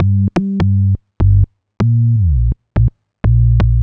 cch_bass_loop_rolled_125_Gm.wav